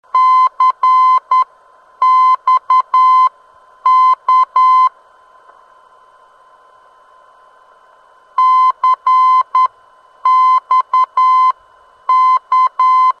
CXK - Bellaire heard on 251 kHz: (91kb)